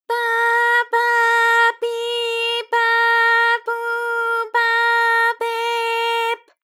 ALYS-DB-001-JPN - First Japanese UTAU vocal library of ALYS.
pa_pa_pi_pa_pu_pa_pe_p.wav